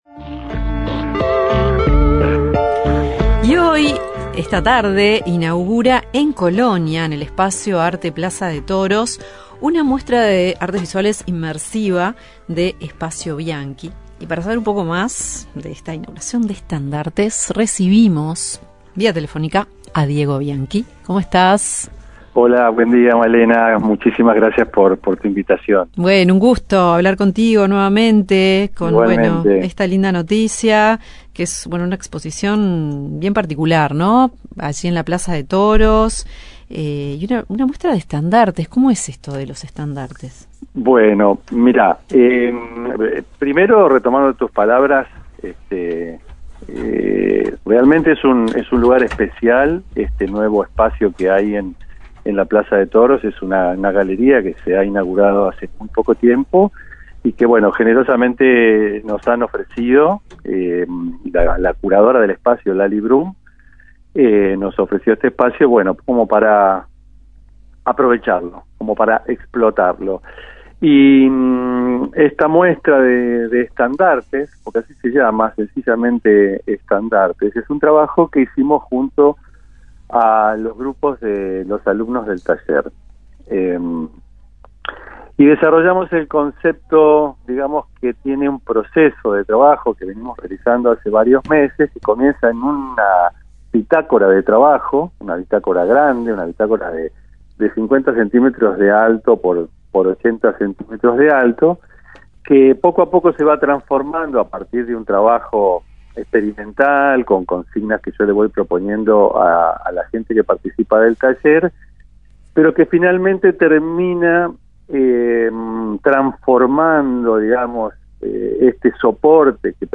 Conversamos con el docente y artista argentino radicado en Uruguay